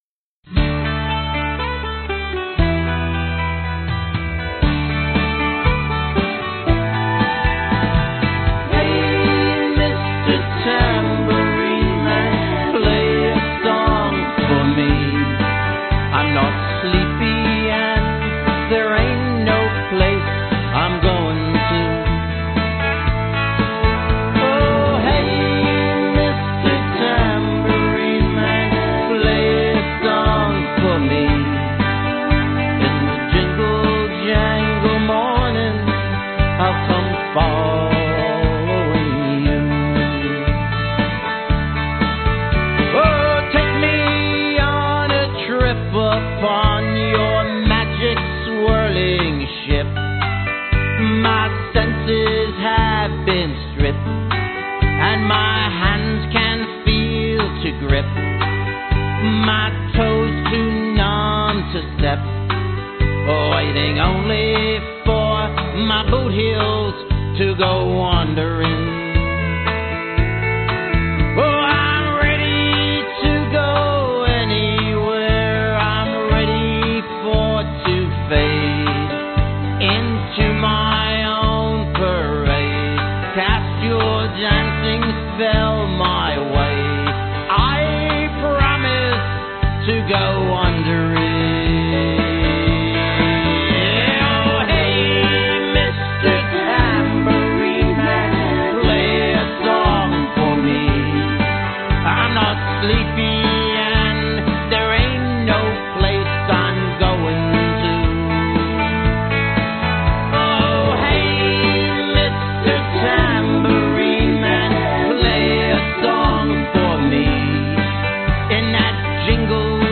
Celebrity singing